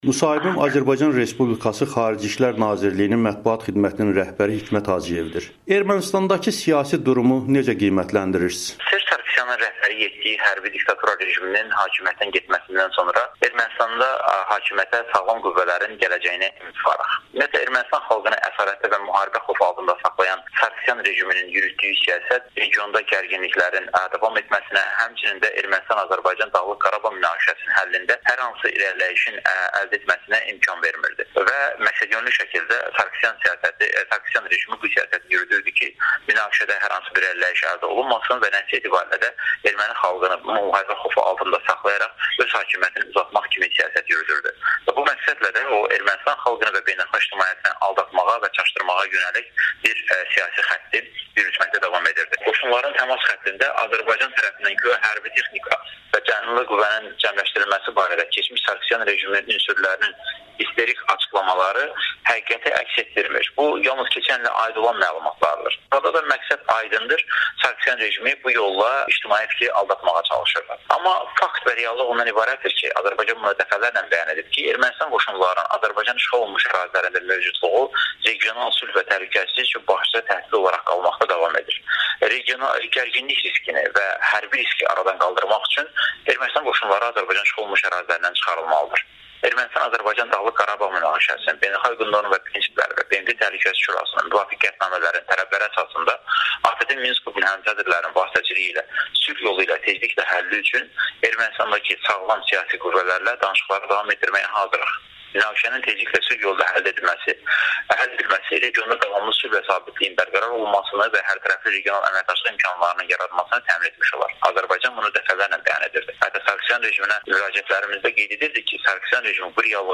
Azərbaycan Xarici İşlər Nazirliyinin Mətbuat xidmətinin rəhbərinin Amerikanın Səsinə müsahibəsi